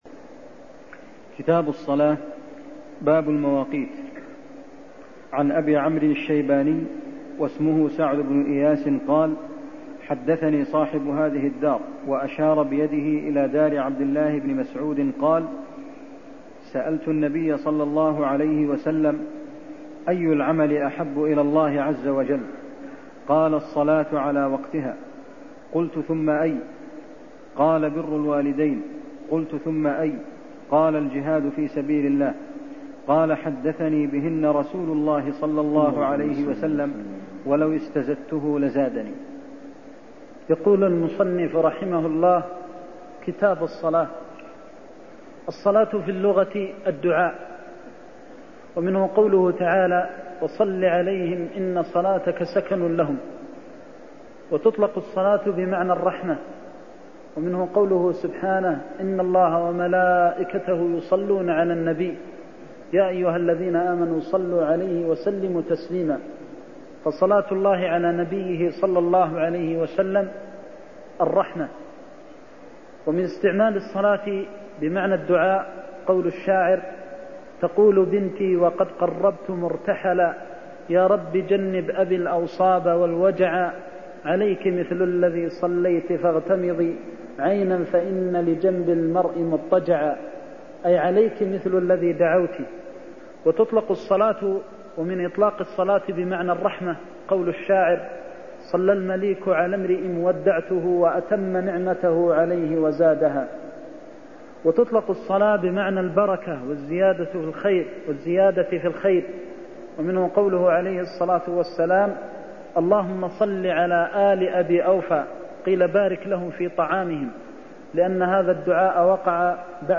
المكان: المسجد النبوي الشيخ: فضيلة الشيخ د. محمد بن محمد المختار فضيلة الشيخ د. محمد بن محمد المختار أي الأعمال أحب إلى الله عز وجل (44)